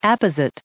282_apposite.ogg